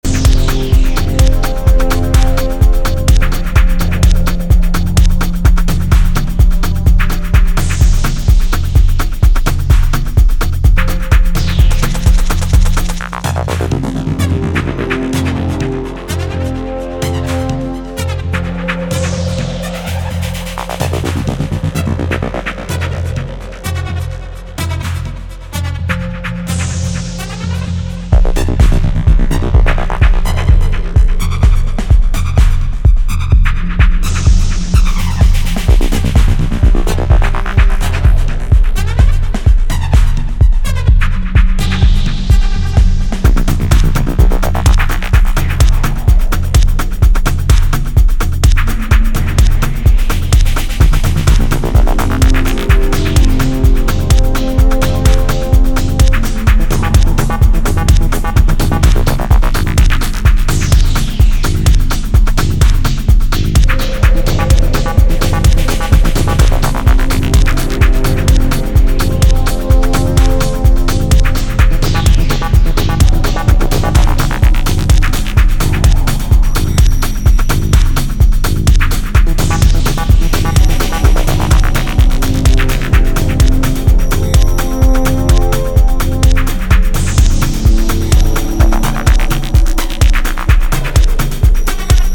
柔くミニマルなボトムに曲がったシンセラインが配された